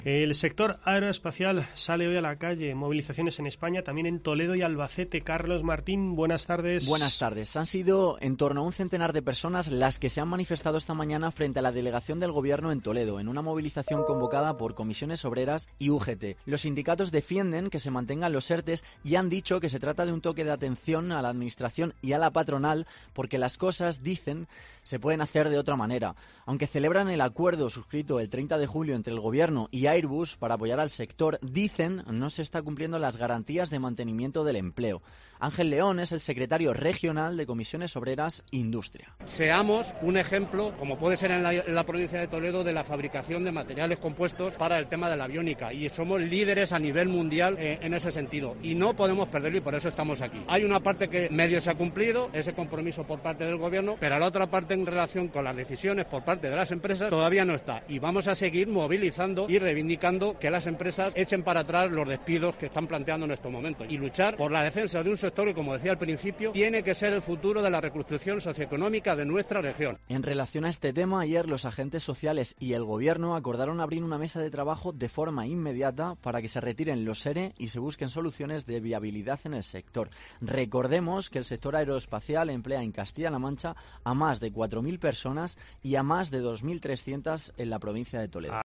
Crónica manifestación sector aeroespacial en Toledo